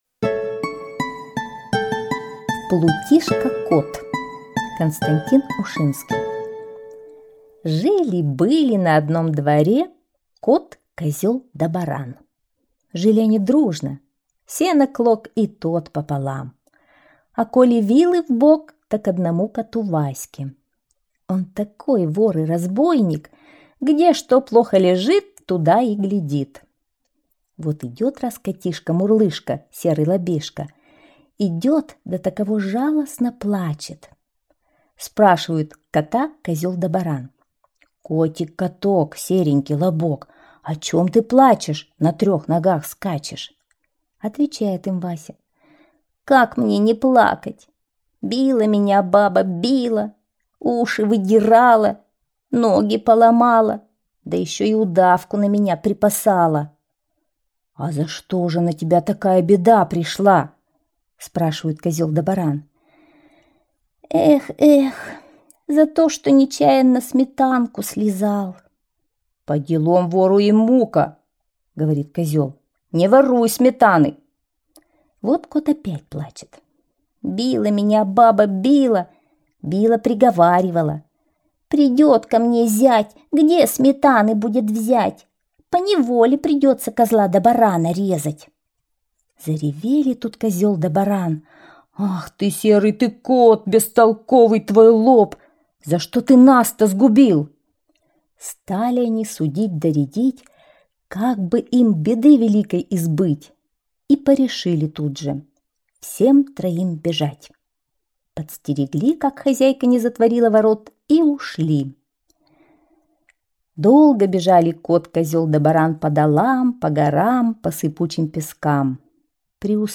Плутишка кот - аудиосказка Ушинского К. Про кота, козла и барана, живших на одном дворе.